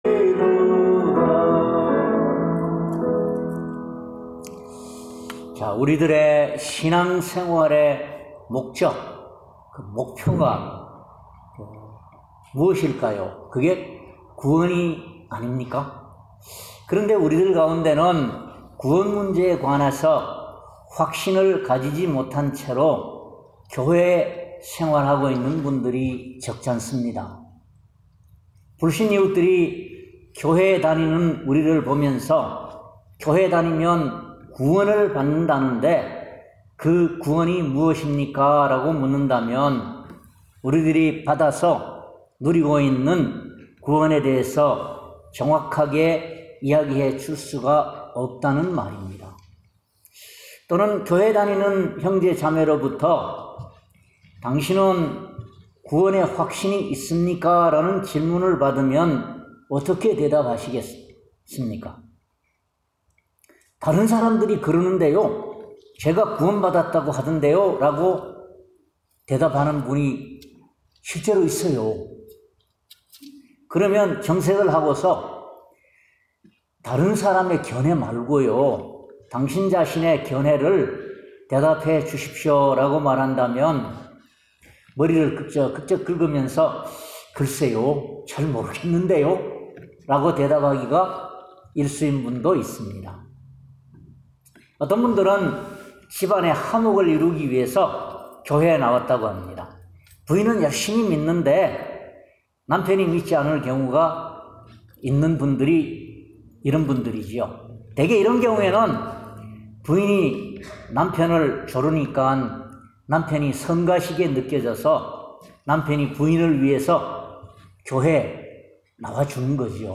Sermon-0703.mp3